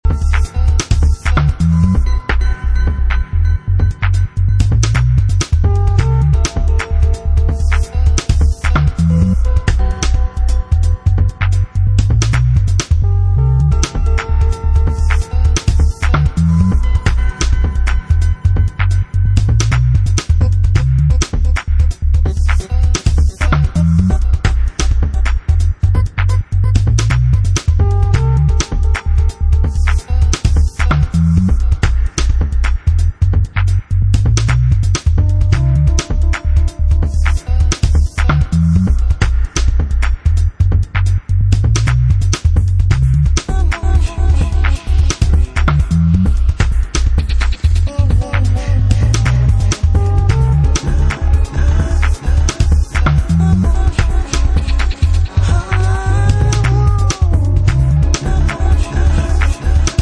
Electronix Bass